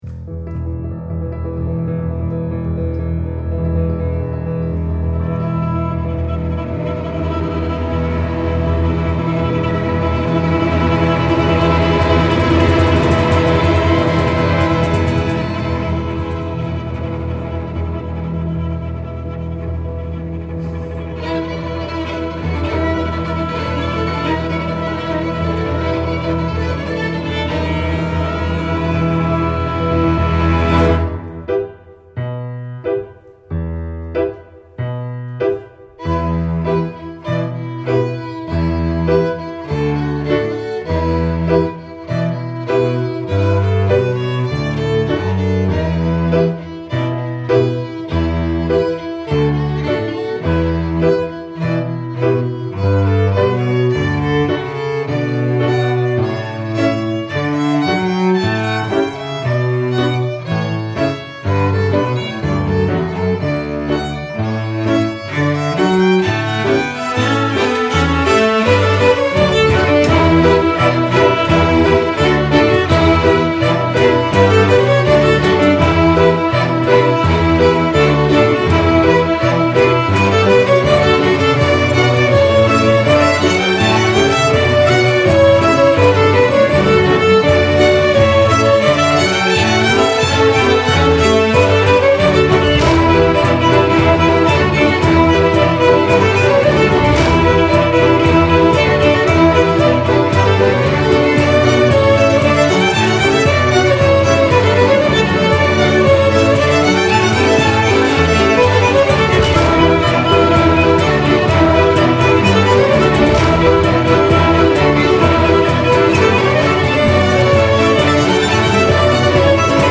Reel   - Page de garde